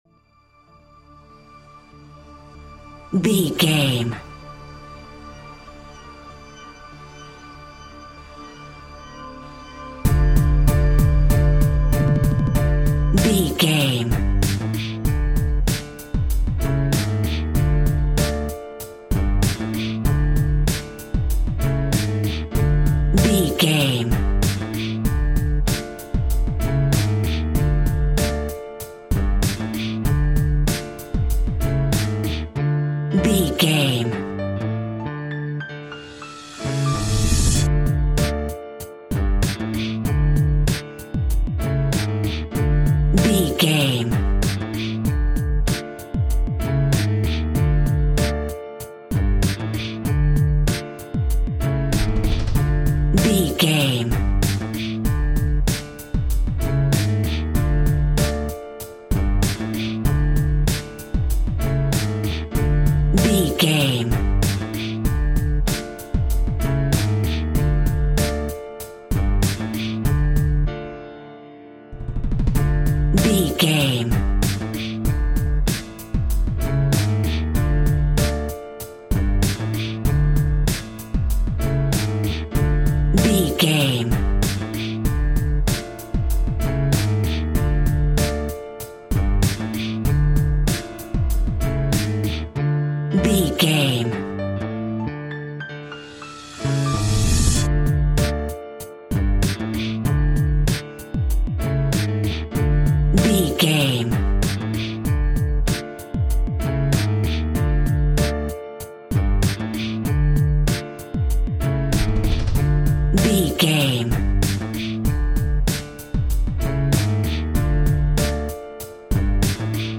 Aeolian/Minor
D
Funk
electronic
drum machine
synths